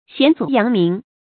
顯祖揚名 注音： ㄒㄧㄢˇ ㄗㄨˇ ㄧㄤˊ ㄇㄧㄥˊ 讀音讀法： 意思解釋： 謂使祖宗顯耀，使名聲傳揚。